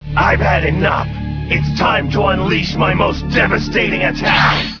gogeta_powerup1.wav